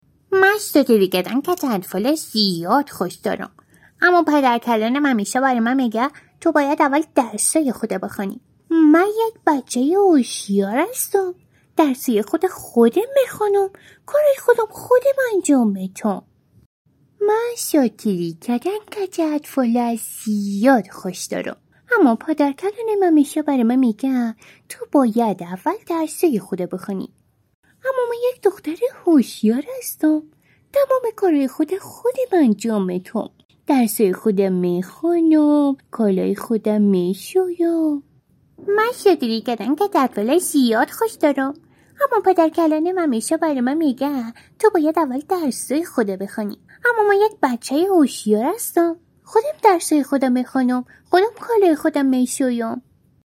Female
Dari-Girl-Kid